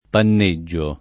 vai all'elenco alfabetico delle voci ingrandisci il carattere 100% rimpicciolisci il carattere stampa invia tramite posta elettronica codividi su Facebook panneggiare v.; panneggio [ pann %JJ o ], -gi — fut. panneggerò [ panne JJ er 0+ ]